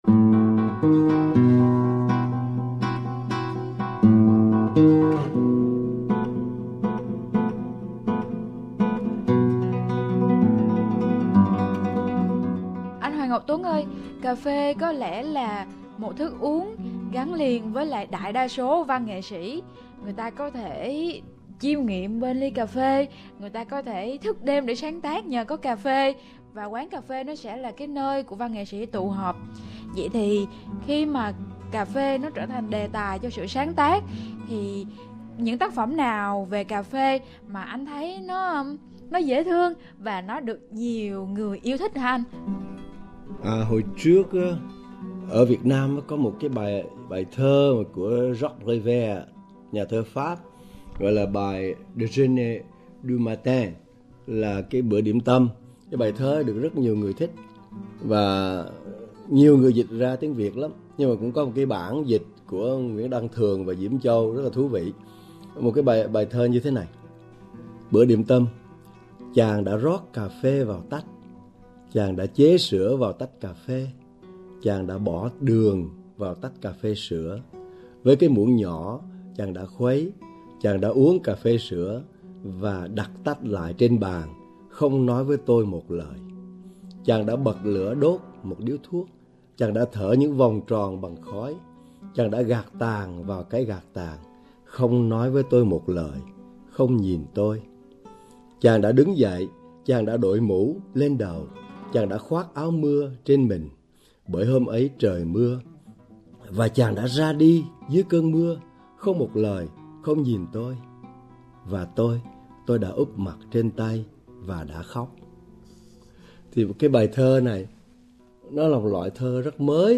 dưới hình thức phỏng vấn